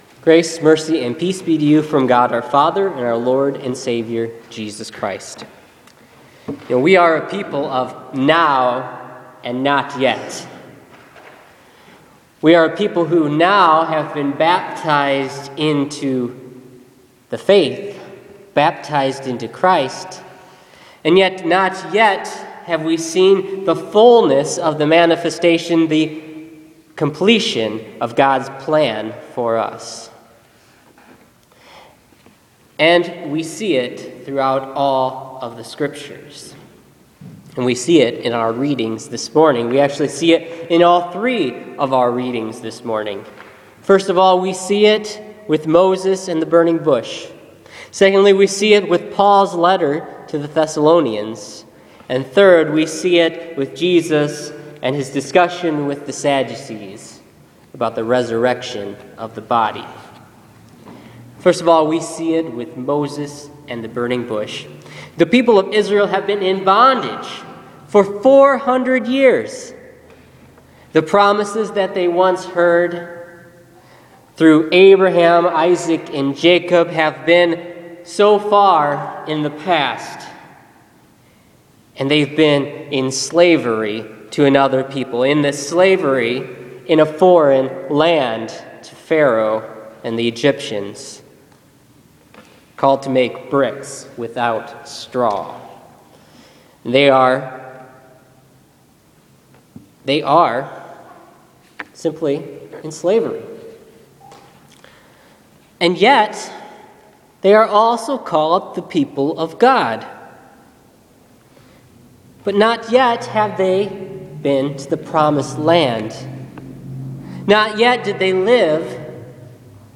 Listen to this week’s sermon for the 25th week after Pentecost.